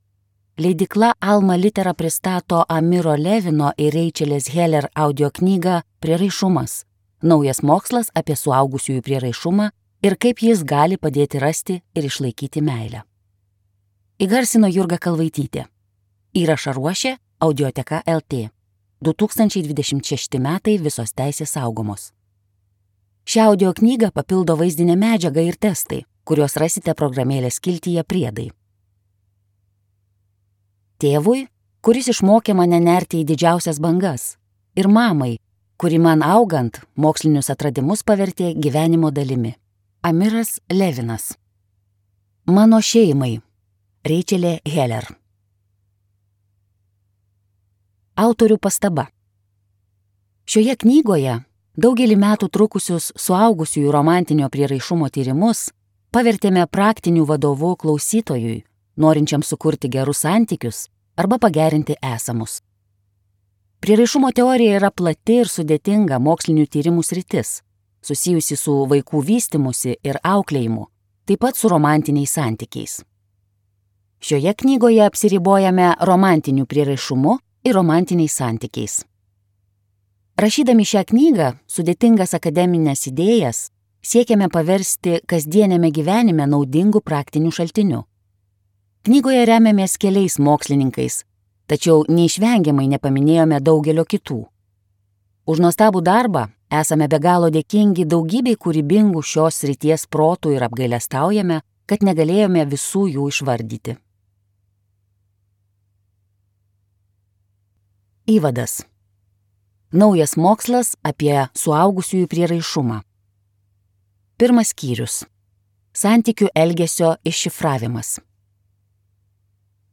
Prieraišumas | Audioknygos | baltos lankos